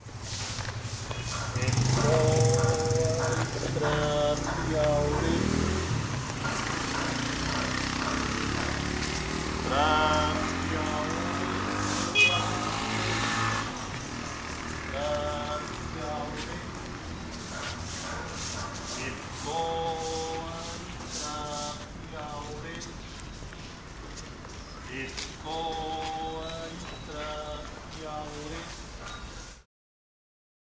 Vendedor de escobas en el Barrio Miraflores
Grabado en Barrio Miraflores, Cali, Colombia. Parte del Proyecto Cali Postales Sonoras.